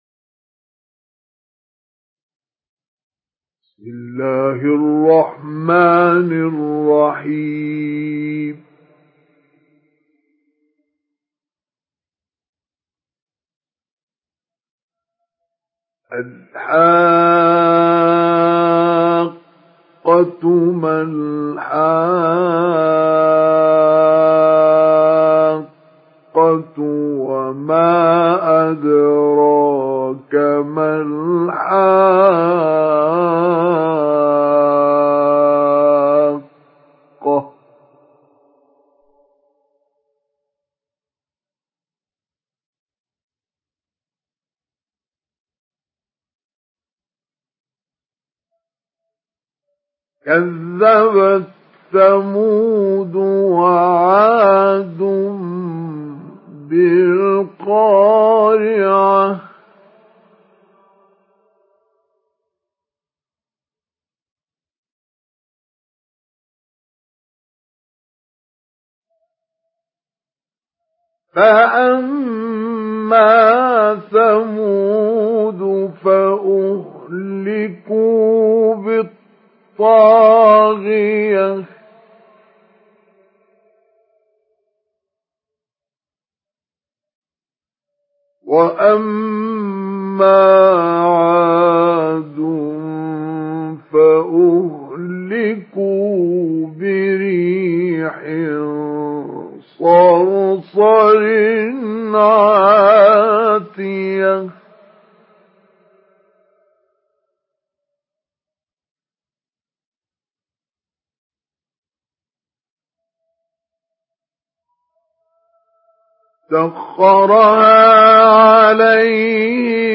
Surah الحاقه MP3 by مصطفى إسماعيل مجود in حفص عن عاصم narration.